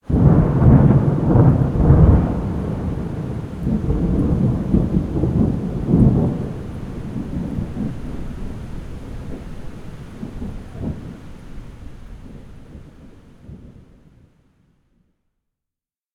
rain_thunder_loop.ogg